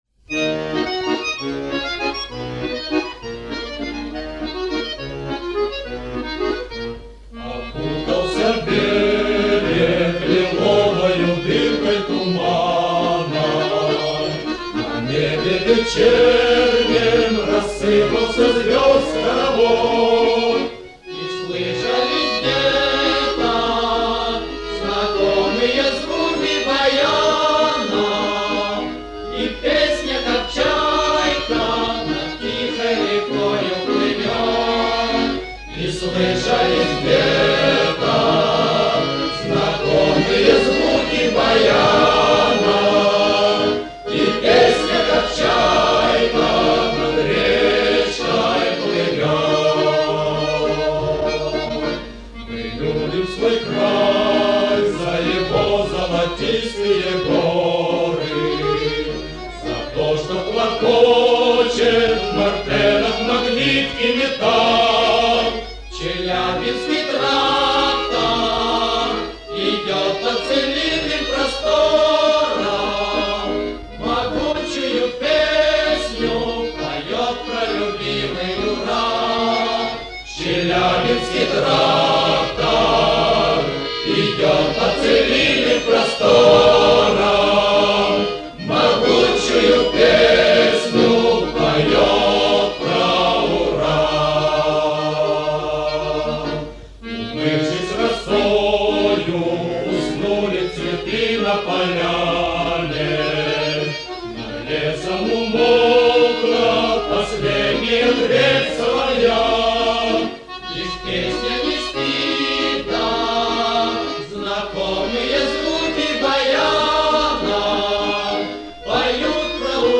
мужская группа